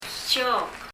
tsiók　　　　[tʃɔ:k]
発音